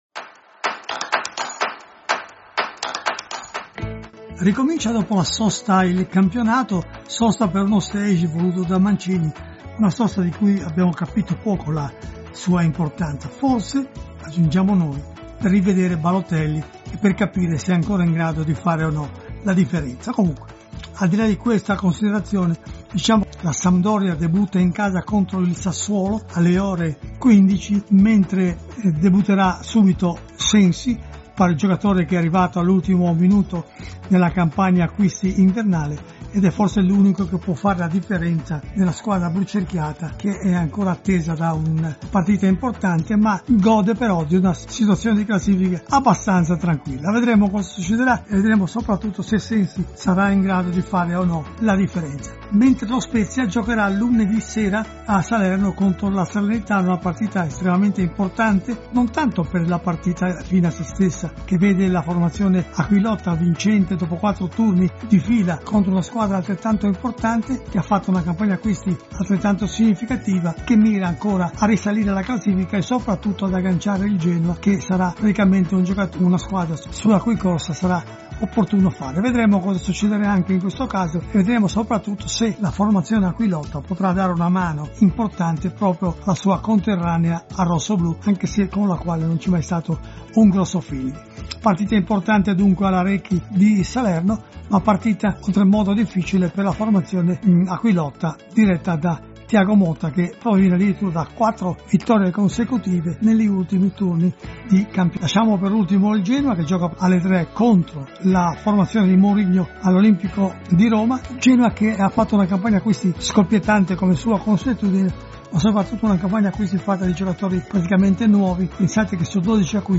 L’audio commento